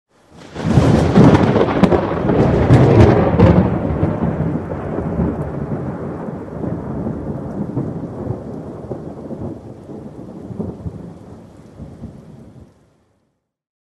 thunder_17.ogg